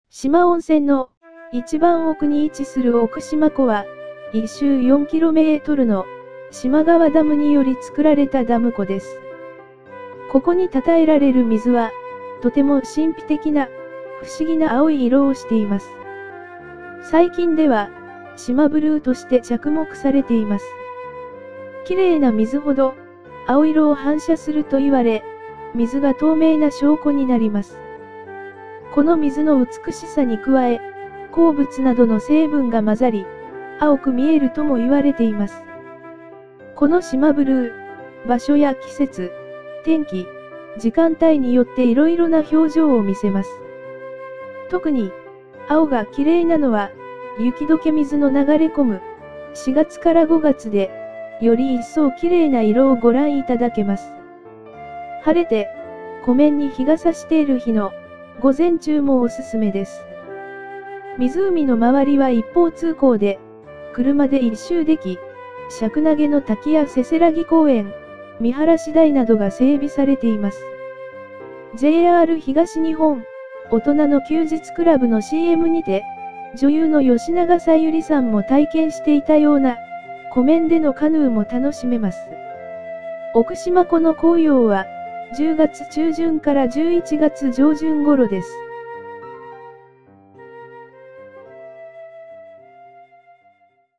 奥四万湖 – 四万温泉音声ガイド（四万温泉協会）